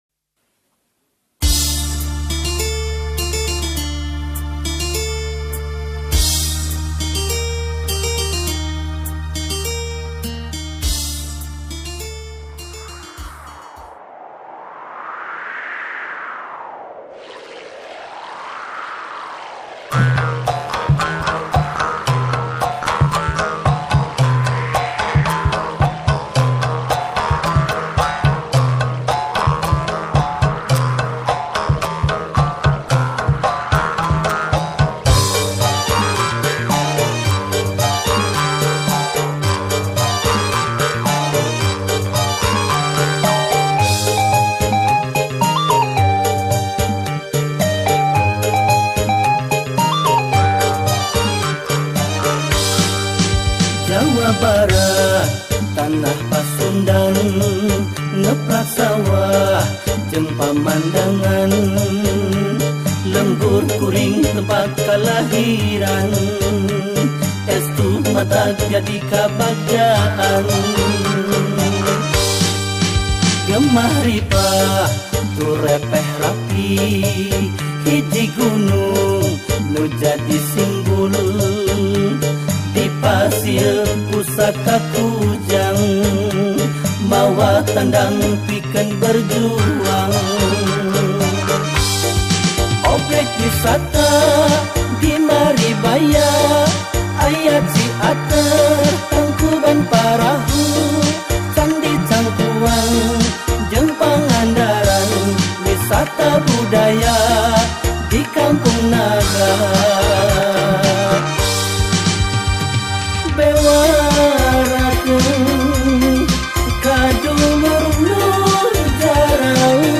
dangdut